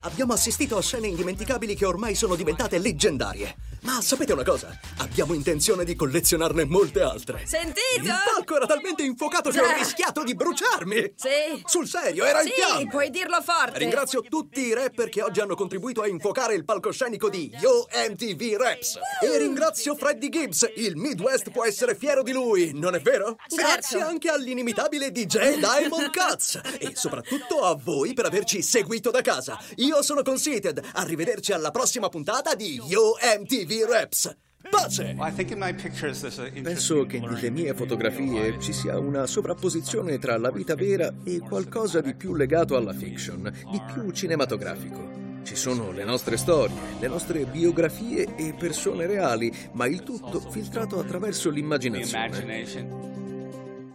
Male
Confident, Corporate, Energetic, Engaging, Friendly, Funny, Sarcastic, Streetwise, Upbeat, Versatile, Warm
ANIMATION.mp3
Microphone: Neumann TLM-103